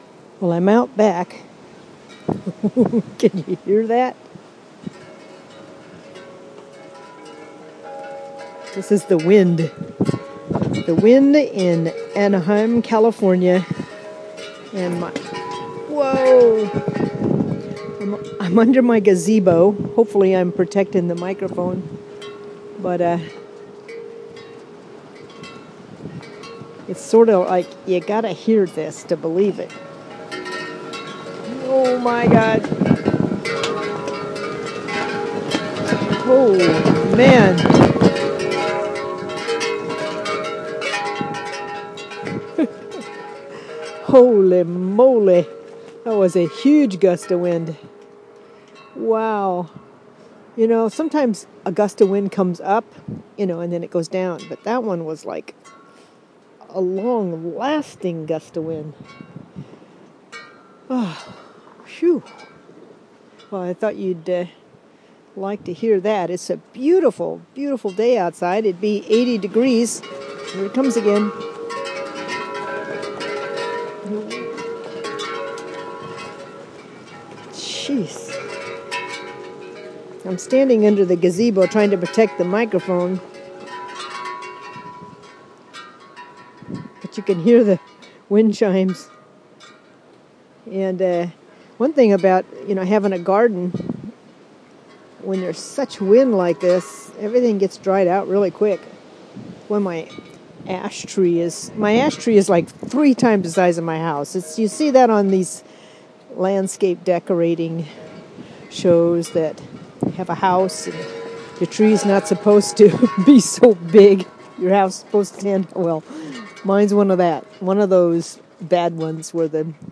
To the Sound of Wind!